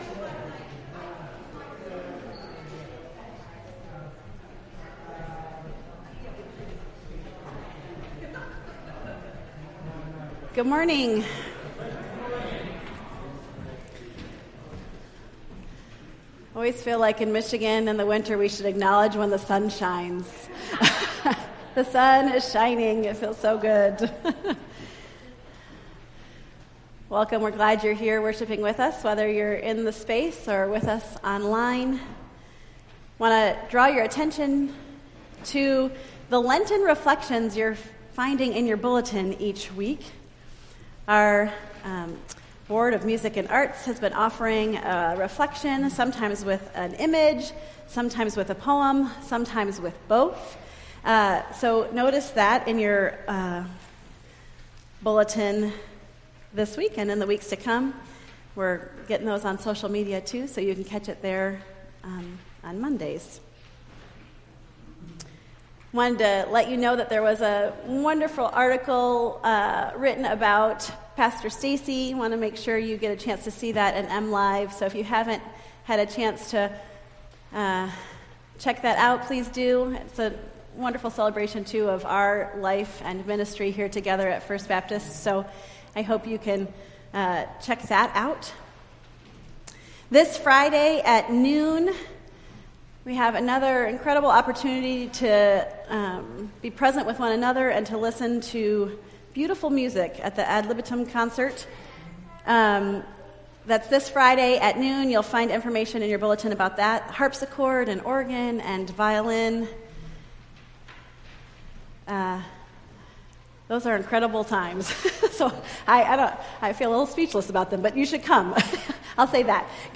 Entire March 19th Service
3-19-23-service.mp3